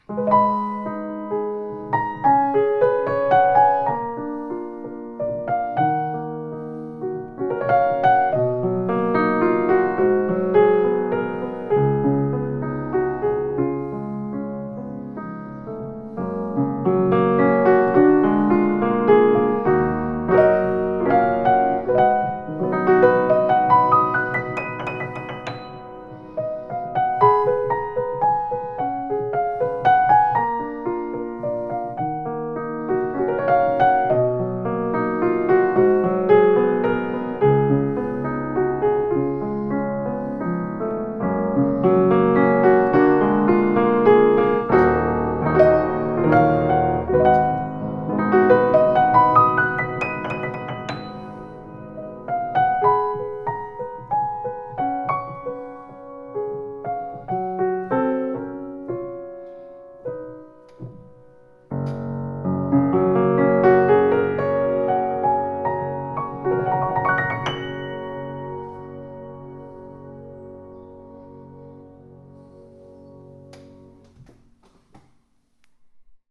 Een prachtige Kawai piano met een mooi groot en compleet geluid als van een vleugel.